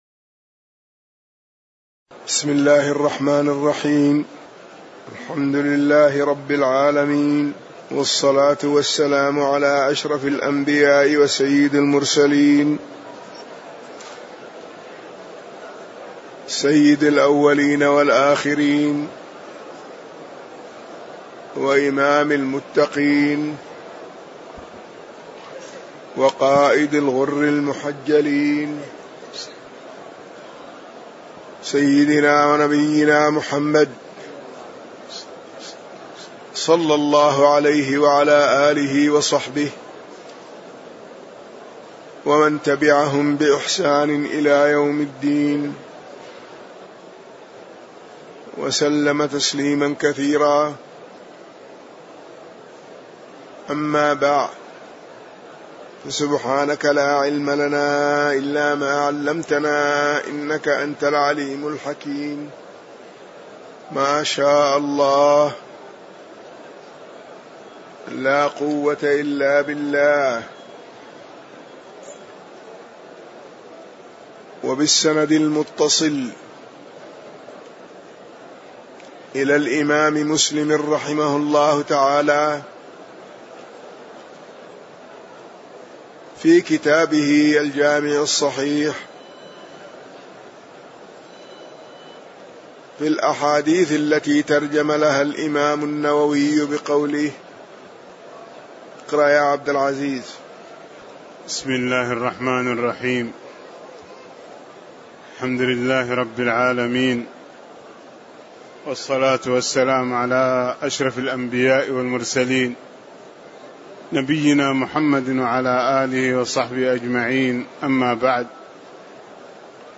تاريخ النشر ١٦ شعبان ١٤٣٧ هـ المكان: المسجد النبوي الشيخ